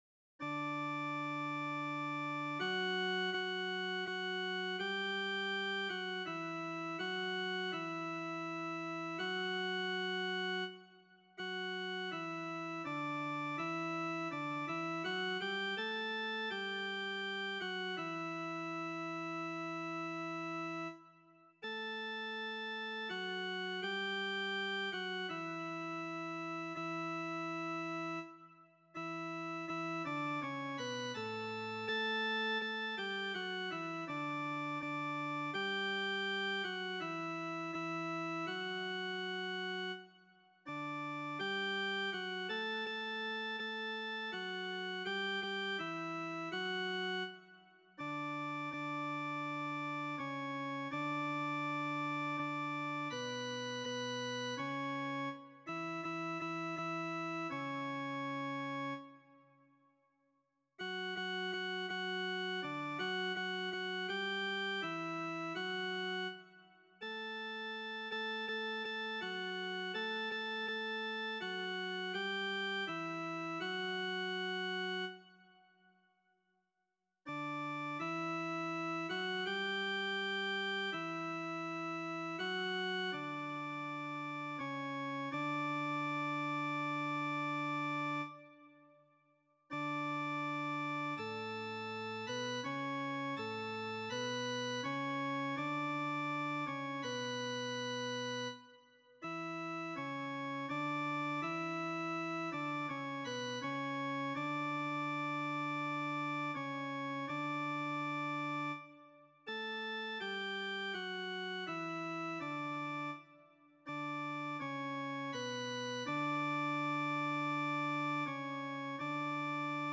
Répétition SATB4 par voix
Soprano
Le décor "O Magnum Mysterium" de Dom Pedro de Christo est une œuvre chorale en quatre parties qui se caractérise par ses harmonies riches et ses mélodies expressives.